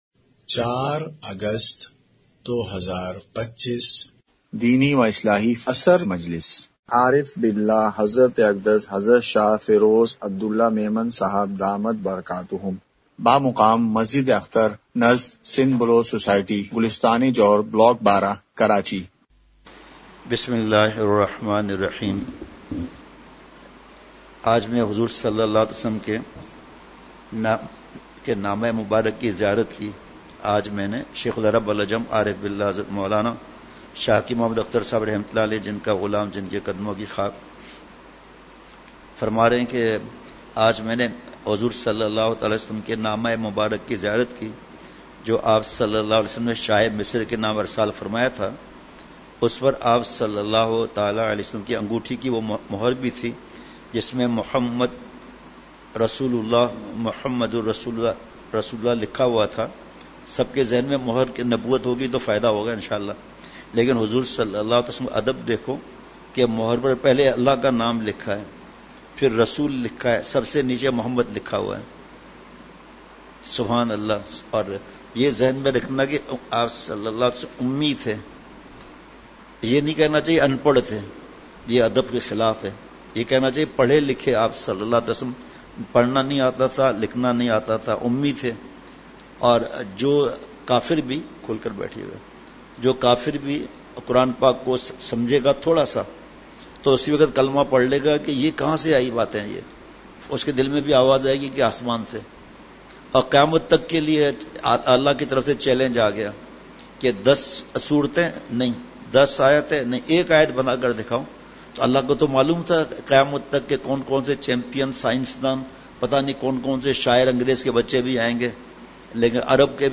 اصلاحی مجلس کی جھلکیاں مقام:مسجد اختر نزد سندھ بلوچ سوسائٹی گلستانِ جوہر کراچی